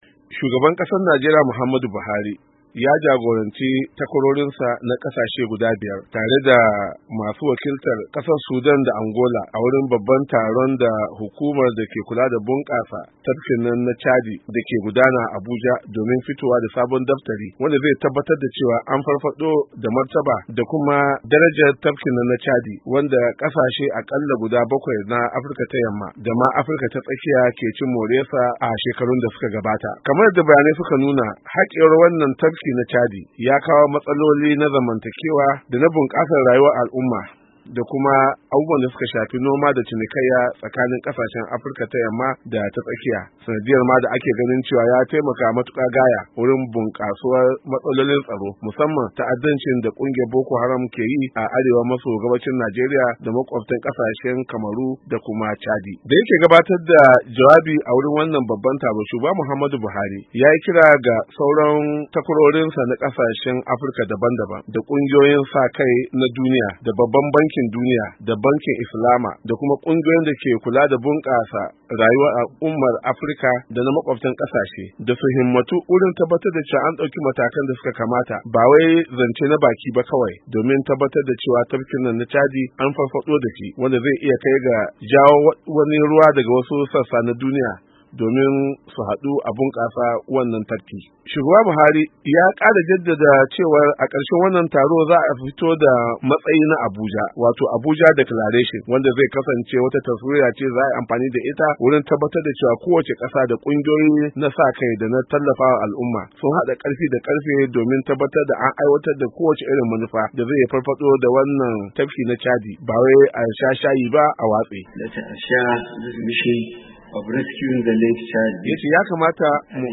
Shugaban Najeriya ya jagoranci sauran kasashen dake kewayen tafkin chadi a taron farfado da tafkin da ya kawo karshe a Abuja.